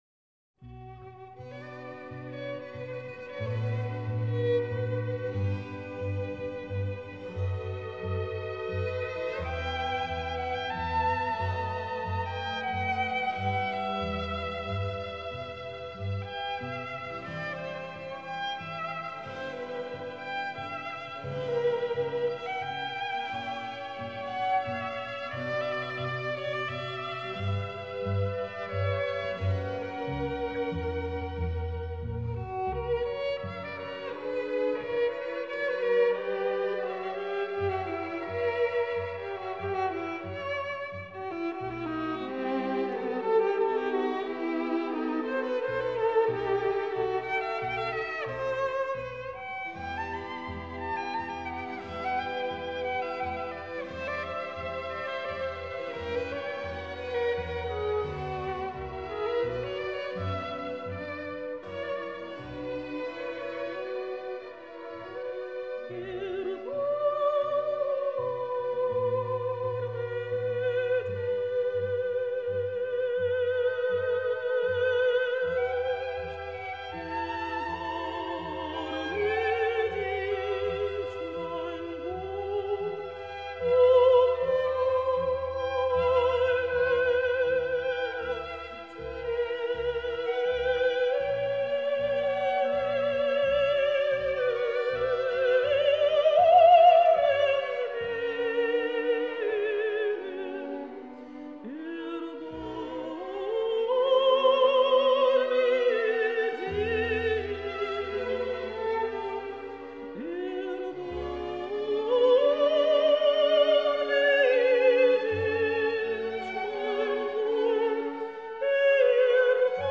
13 августа. День рождения выдающейся русской певицы, Народной артистки РСФСР Валентины Левко